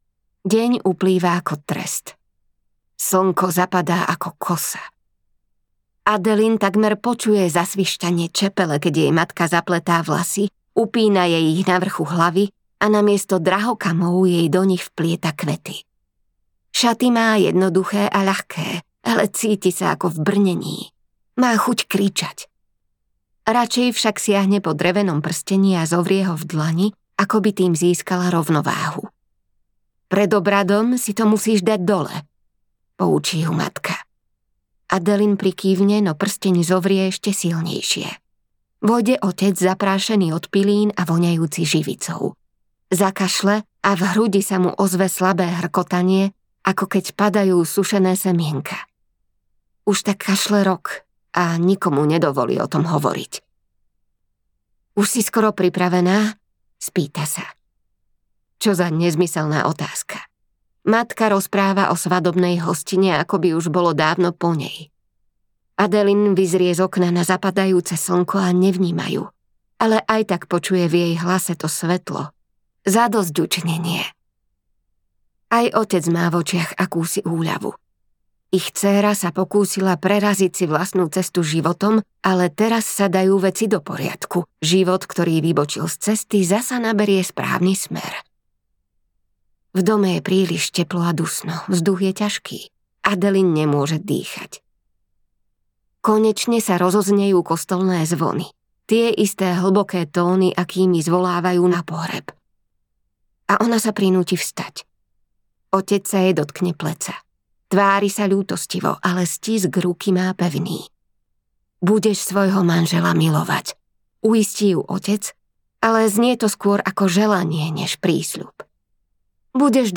Neviditeľný život Addie LaRueovej audiokniha
Ukázka z knihy